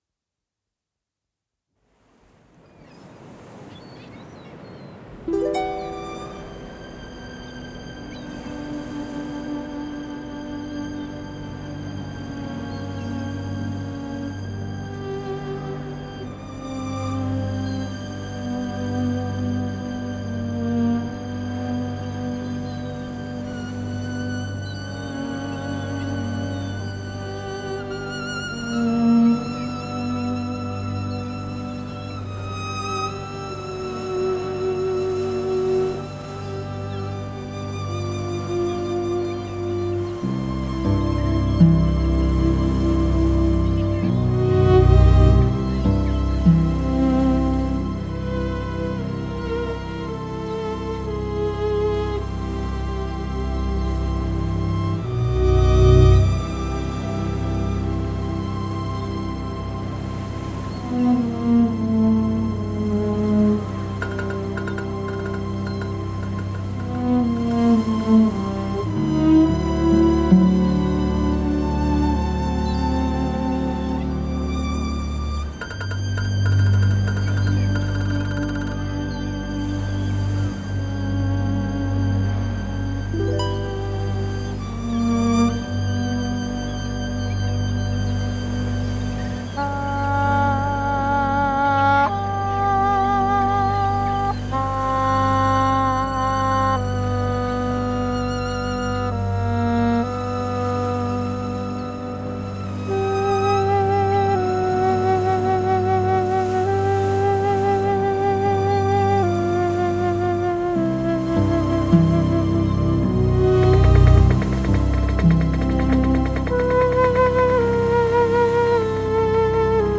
Genre: Jazz.